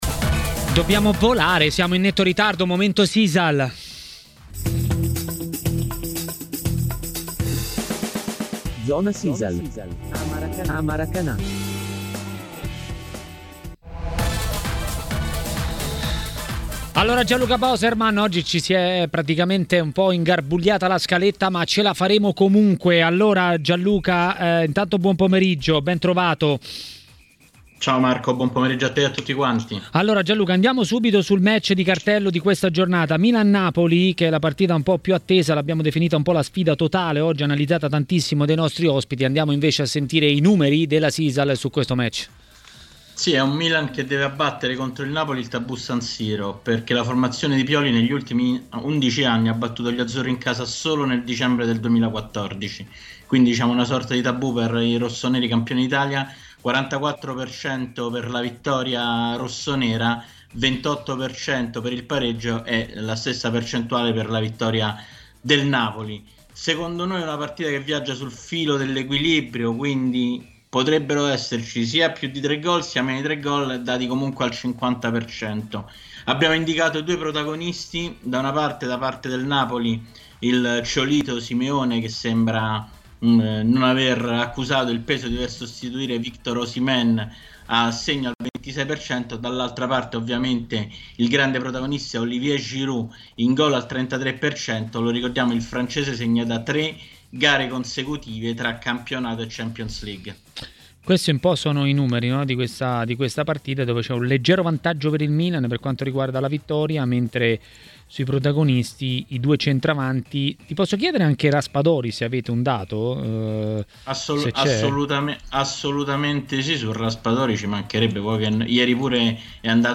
A parlare del prossimo turno di campionato a Maracanà, nel pomeriggio di TMW Radio, è stato il direttore Mario Sconcerti.